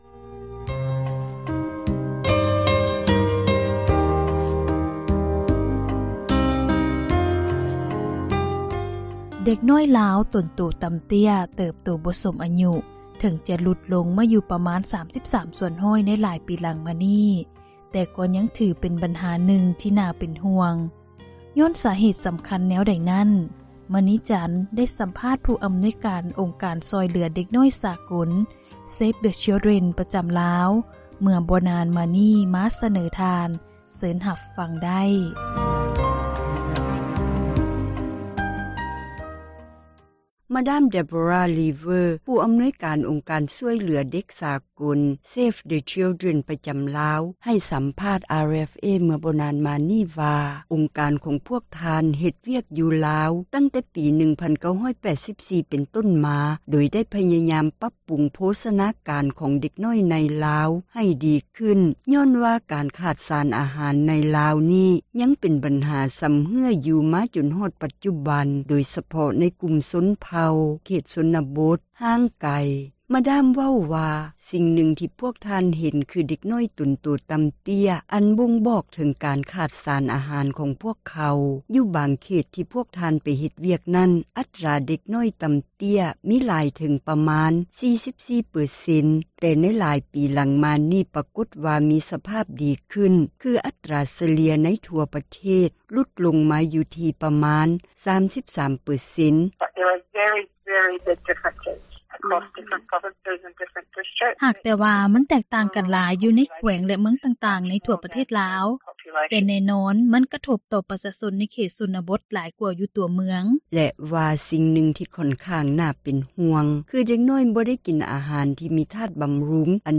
ໃຫ້ສັມພາດ RFA ຜ່ານທາງໂທຣະສັບ ຈາກນະຄອນຫລວງວຽງຈັນ